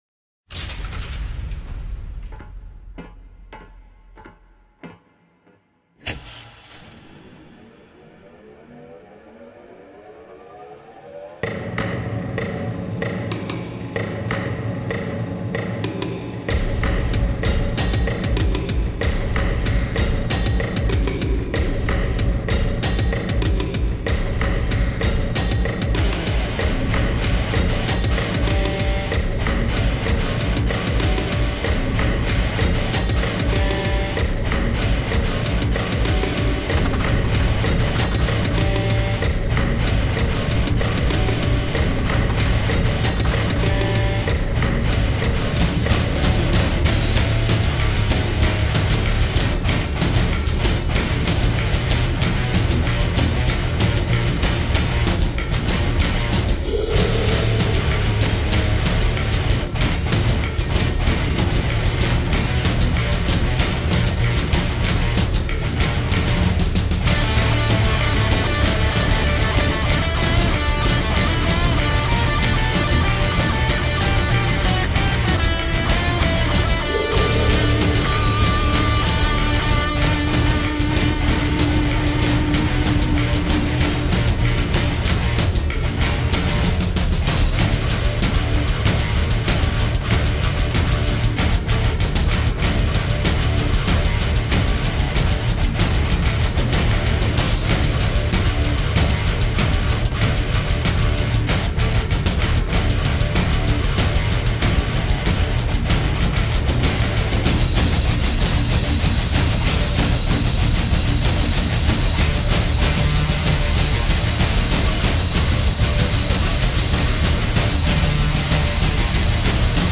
mechanical industrial like drum program
steam punk kind of heavy metal song